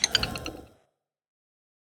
Minecraft Version Minecraft Version latest Latest Release | Latest Snapshot latest / assets / minecraft / sounds / block / vault / deactivate.ogg Compare With Compare With Latest Release | Latest Snapshot
deactivate.ogg